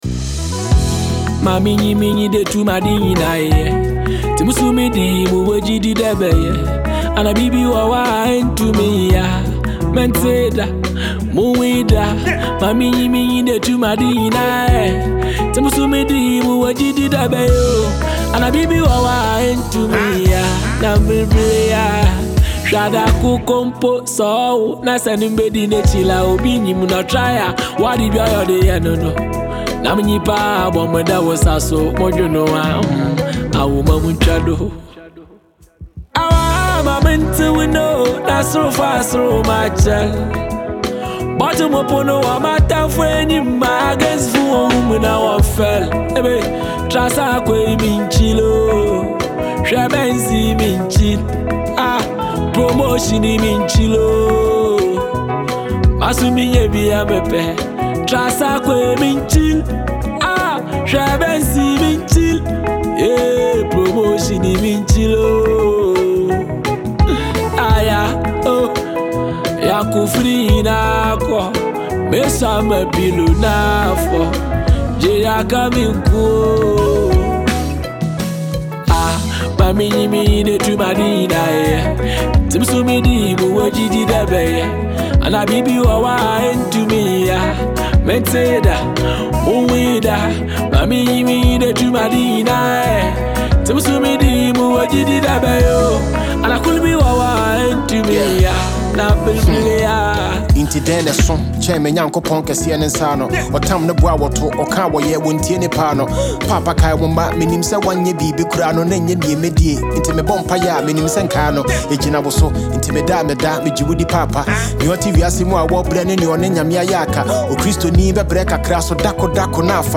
a powerful highlife and rap fusion